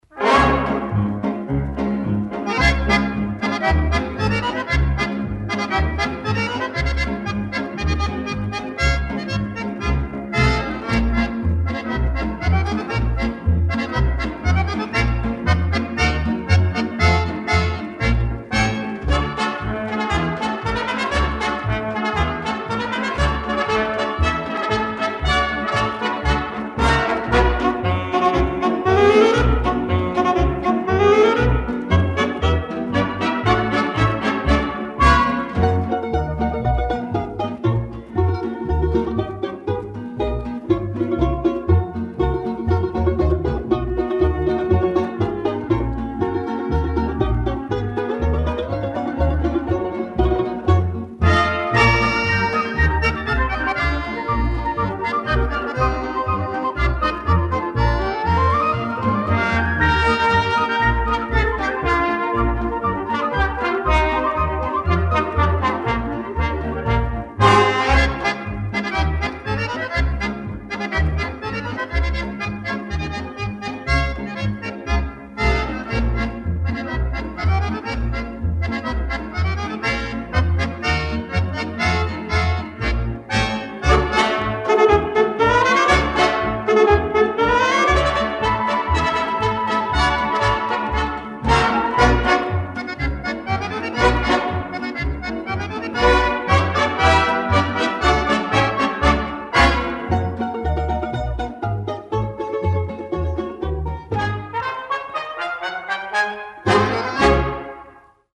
Марш